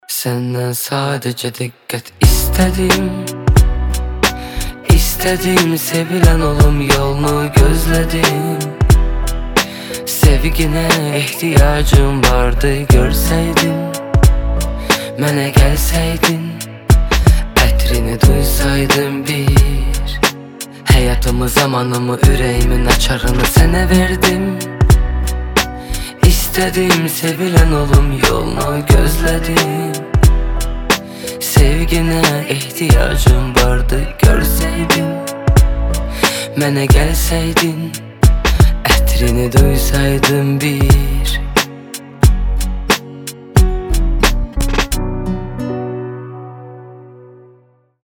Романтические рингтоны
Поп